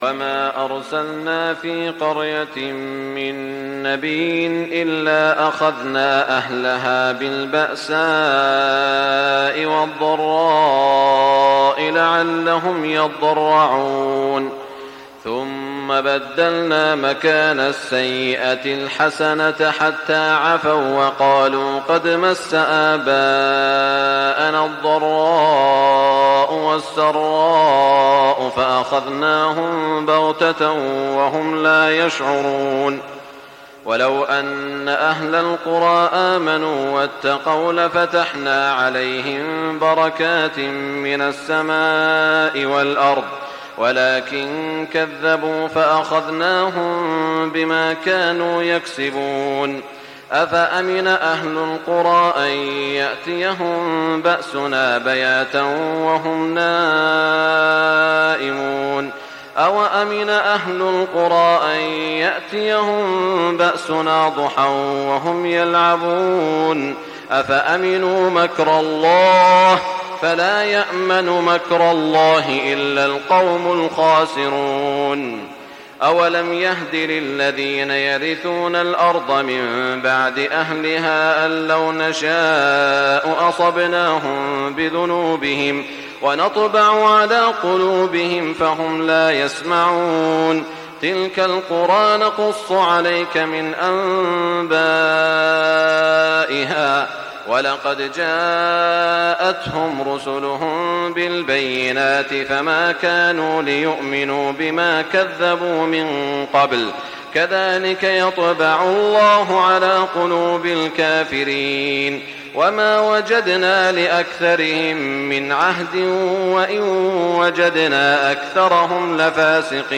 تهجد ليلة 29 رمضان 1420هـ من سورة الأعراف (94-188) Tahajjud 29 st night Ramadan 1420H from Surah Al-A’raf > تراويح الحرم المكي عام 1420 🕋 > التراويح - تلاوات الحرمين